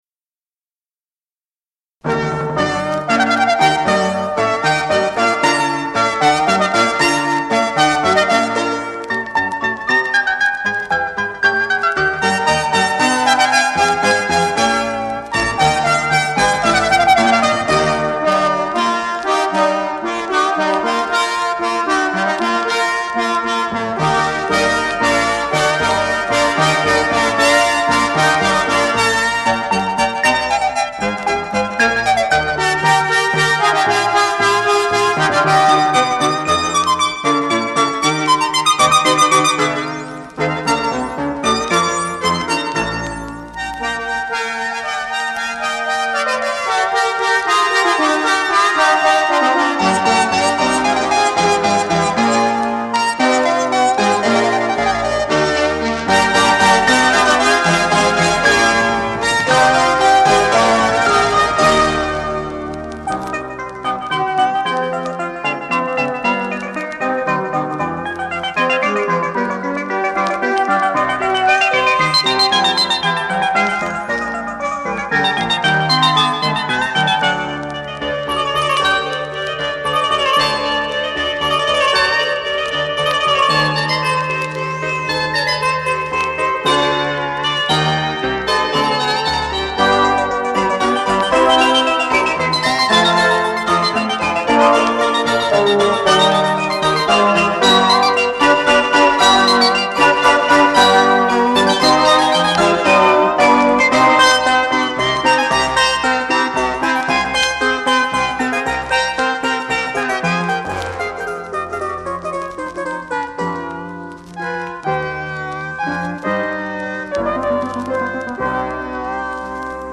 Virtuoso electronic performances